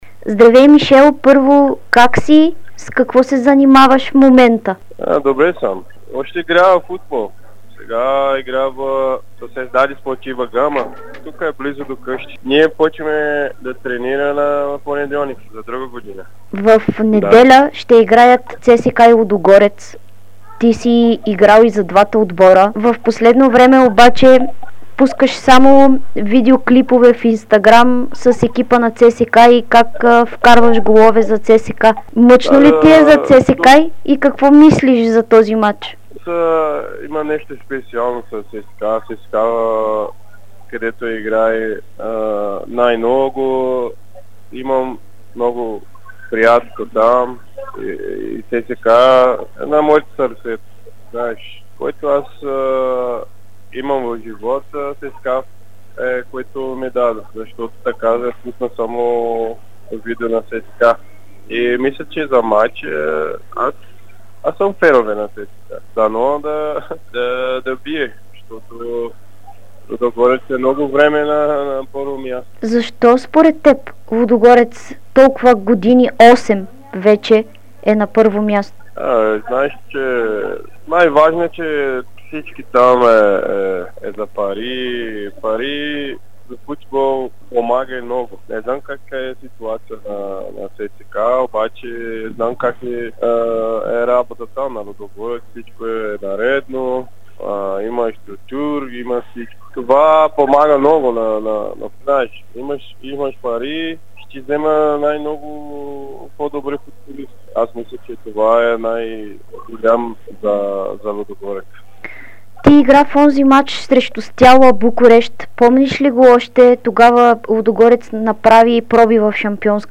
Той говори на добър български език. Ето какво сподели той от Бразилия.